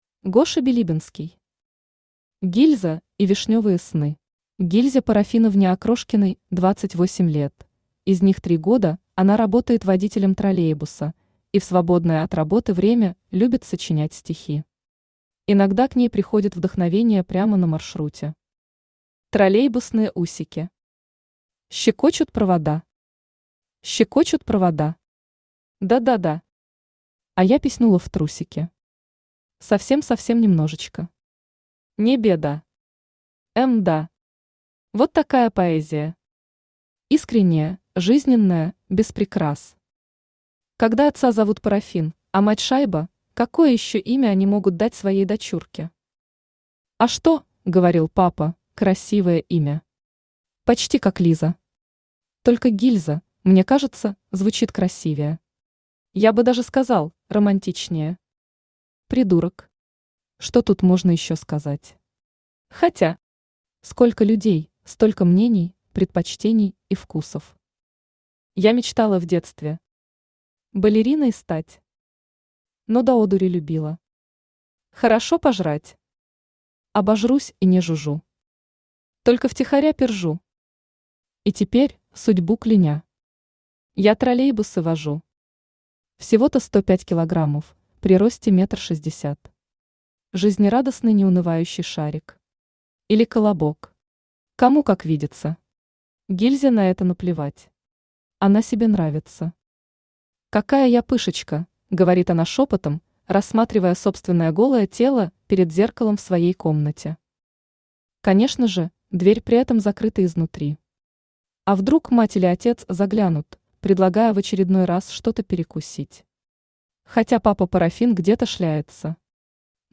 Аудиокнига Гильза и вишнёвые сны | Библиотека аудиокниг
Aудиокнига Гильза и вишнёвые сны Автор Гоша Билибинский Читает аудиокнигу Авточтец ЛитРес.